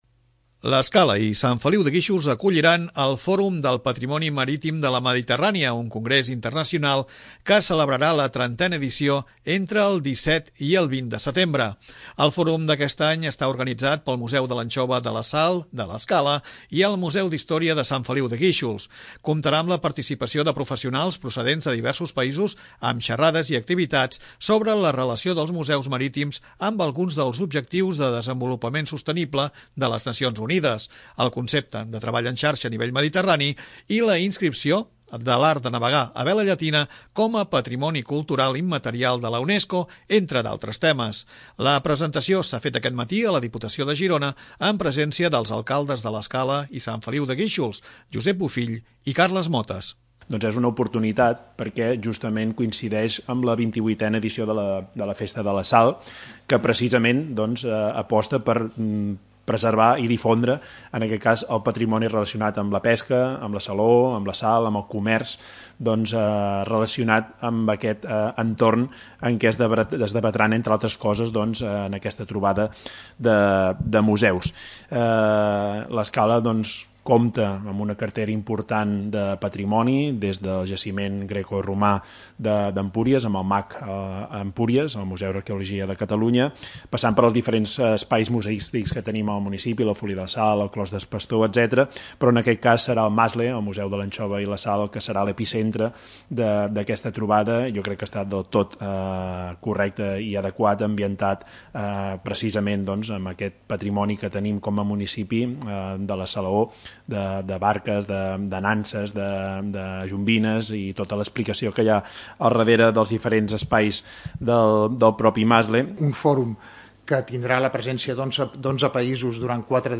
La presentació s'ha fet aquest matí a la Diputació de Girona amb presència dels alcaldes de l'Escala i Sant Feliu de Guíxols, Josep Bofill i Carles Motas.
Des de la Diputació de Girona,  el seu Vicepresident i Diputat de Cultura, Jordi Camps, parlava de la continuïtat d'aquesta trobada, cosa que li dona prestigi.